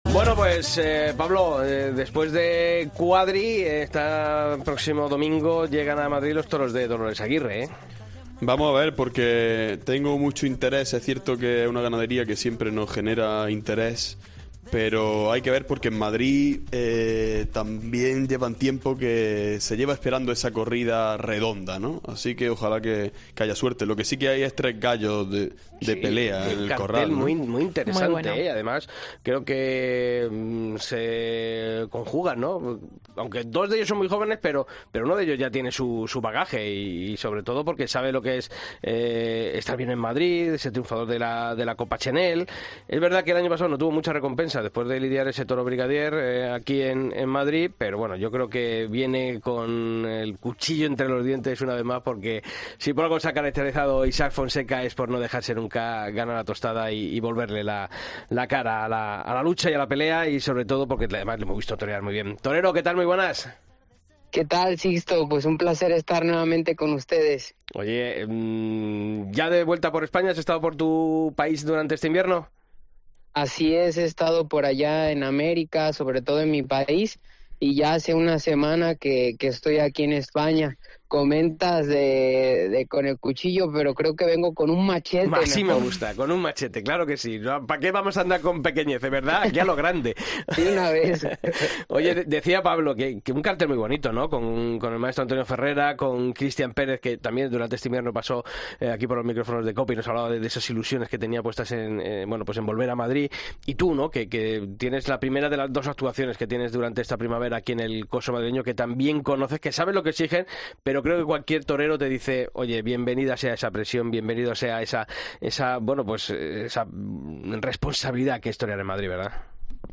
En una entrevista en el programa El Albero de la Cadena COPE, el diestro mexicano ha asegurado que llega con la máxima ambición a su primer compromiso en Las Ventas .